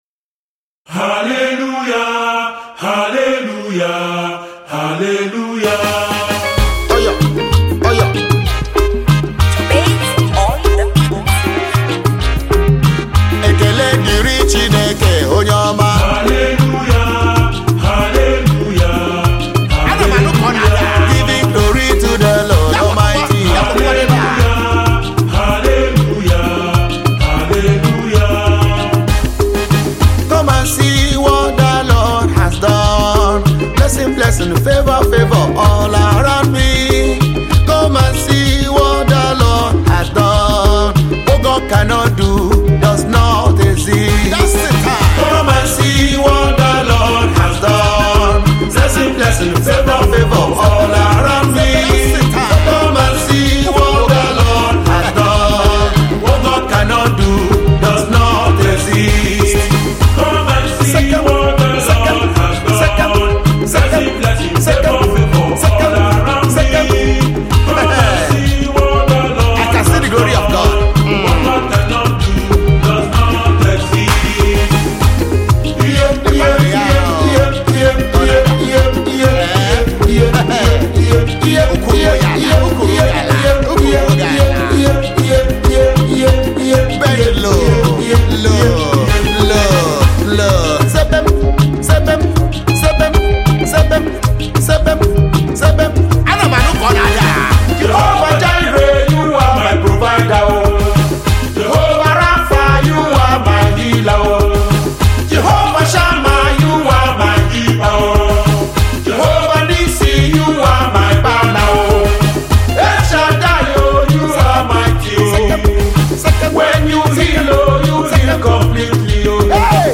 This vibrant gospel highlife anthem
traditional African rhythms with contemporary gospel music